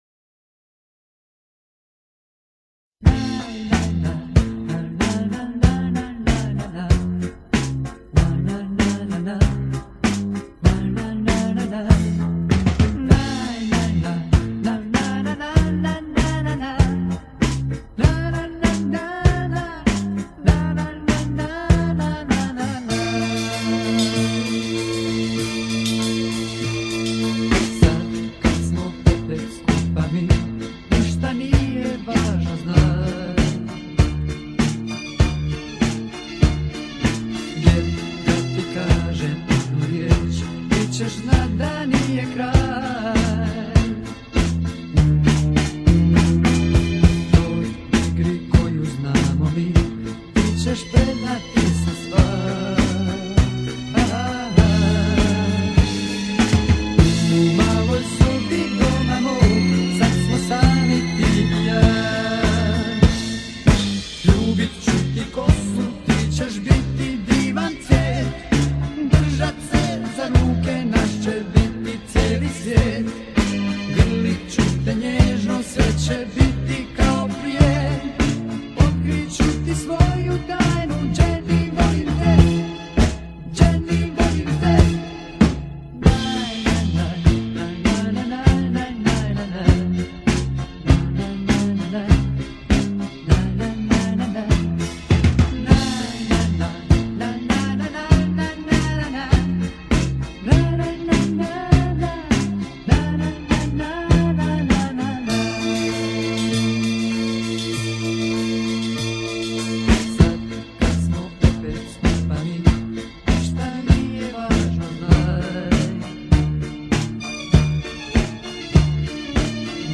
Инструментальная версия старинной шведской песенки Маленькая красавица Анна.